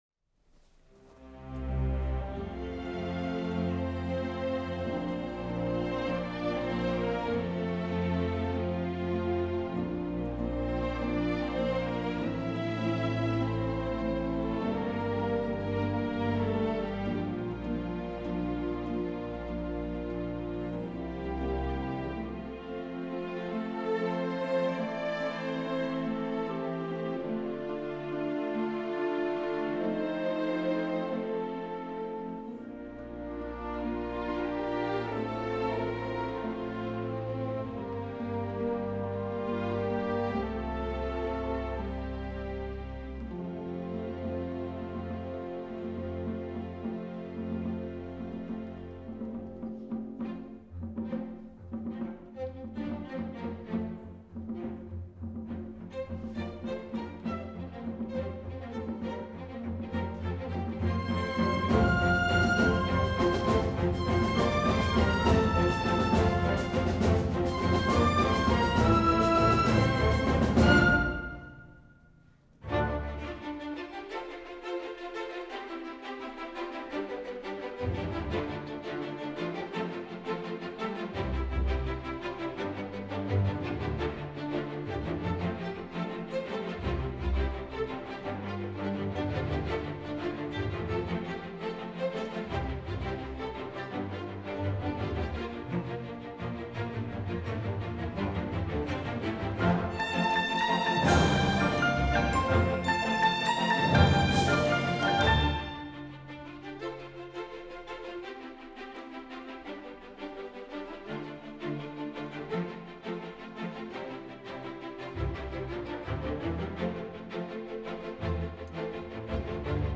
Live-Performance: